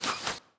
archer_volley.wav